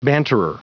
Prononciation du mot banterer en anglais (fichier audio)
Prononciation du mot : banterer